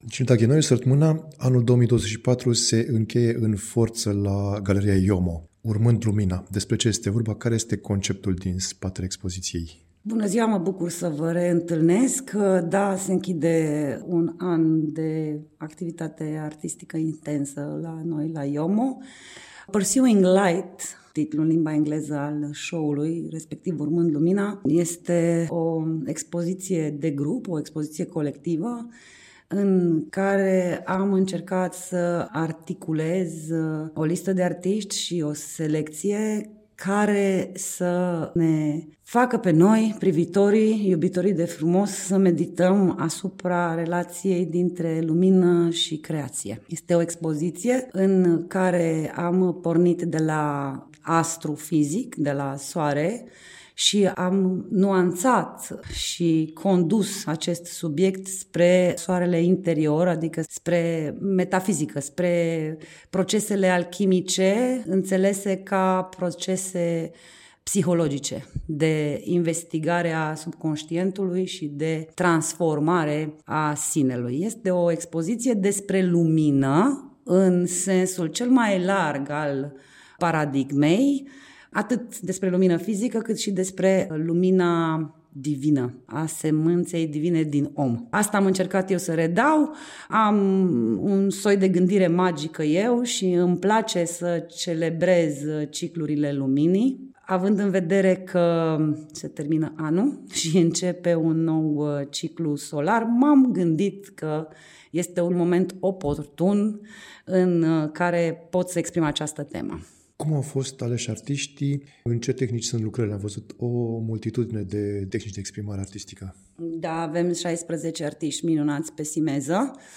Am discutat după vernisaj